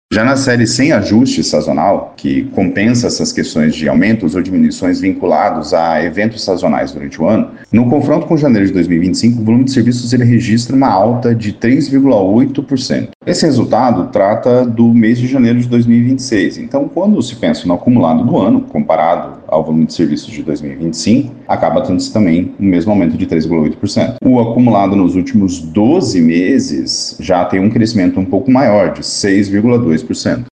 Em entrevista à FM Educativa MS, explicou: